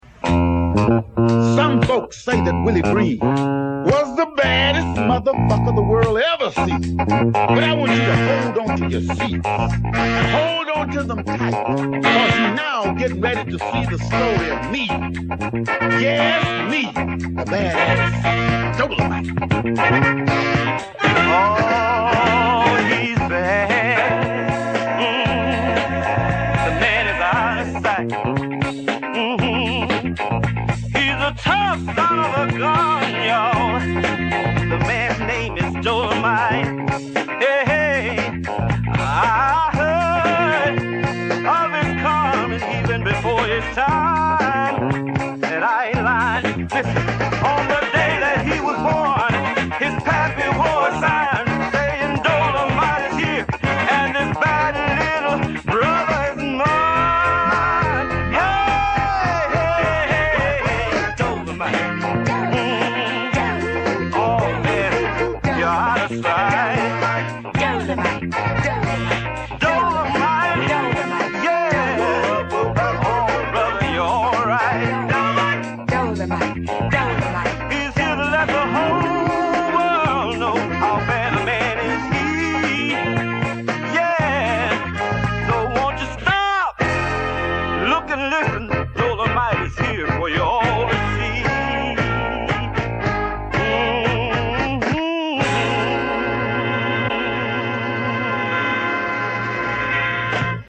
Movie Soundtrack